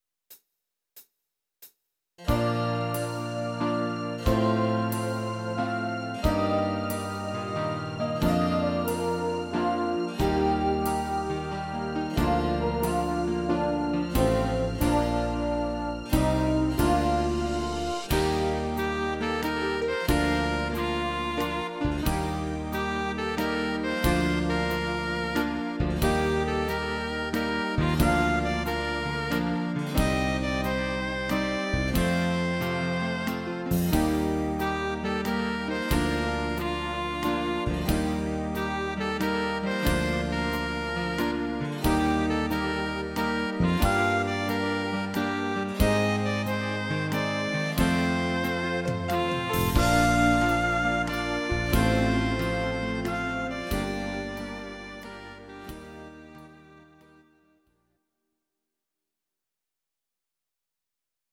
These are MP3 versions of our MIDI file catalogue.
Please note: no vocals and no karaoke included.
waltz